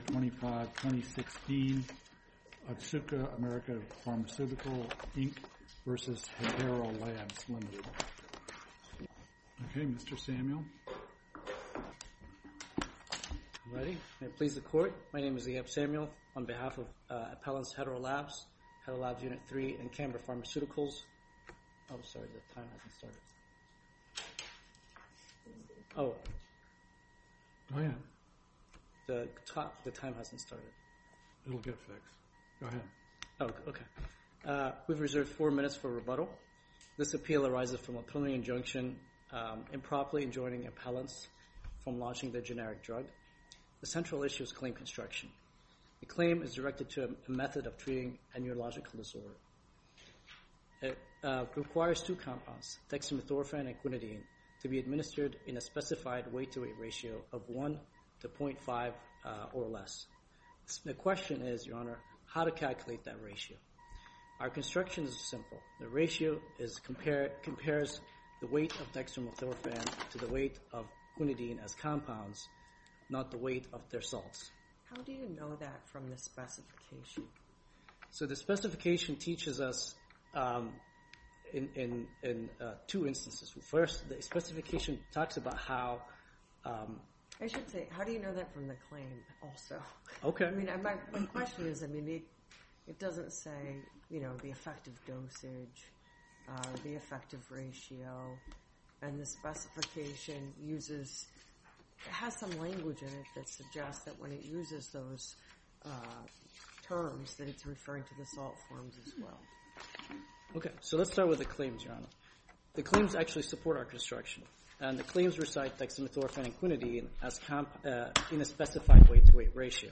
Federal Circuit Oral Argument AI Transcripts